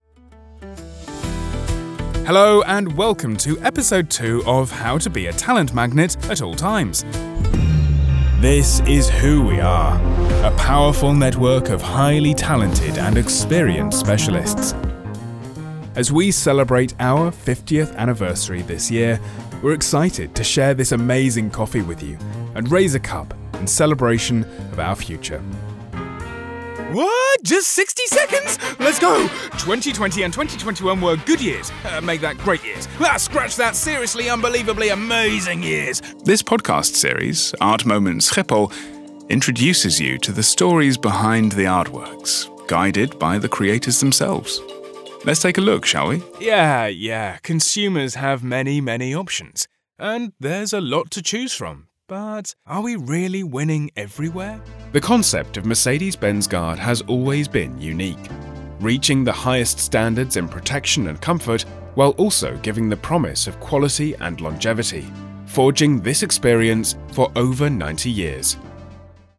English (British)
Natural, Reliable, Warm, Friendly, Corporate
Corporate